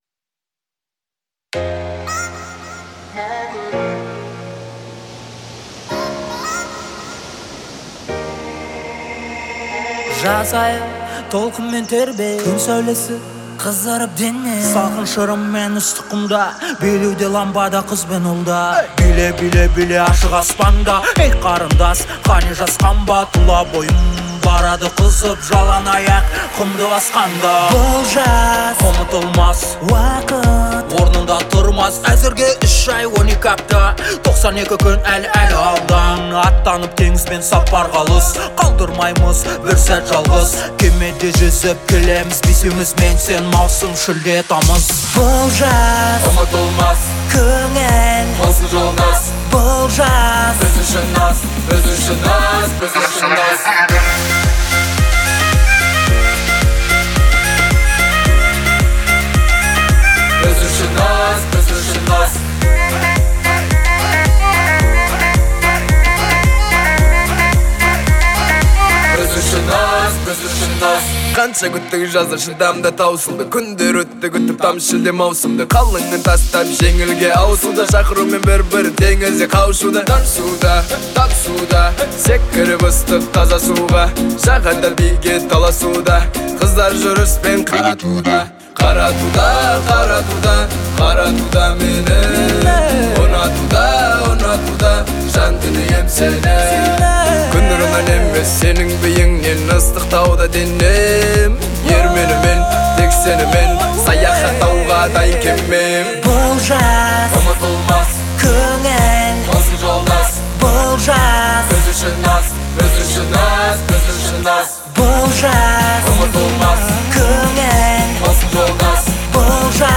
это яркая и энергичная композиция в жанре хип-хоп